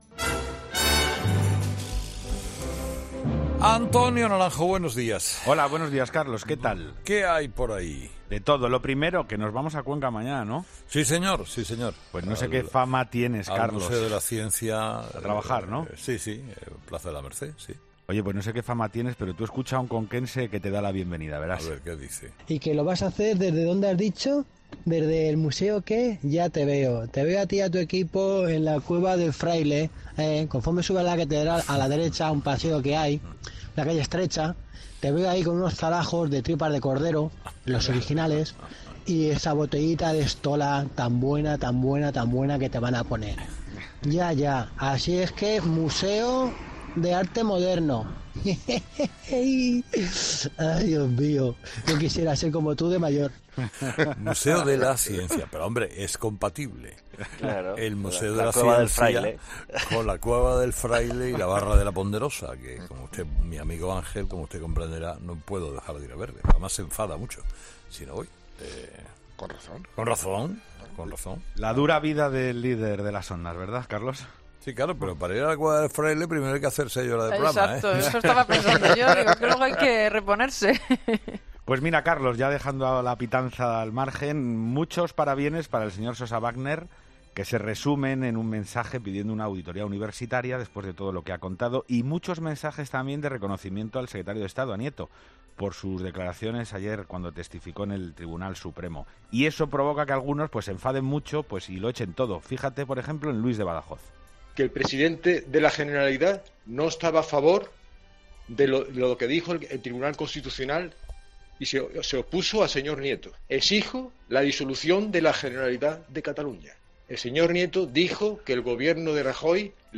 Los oyentes han vuelto a expresar su malestar sobre determinadas situaciones que se produjeron en Cataluña el día del referéndum ilegal.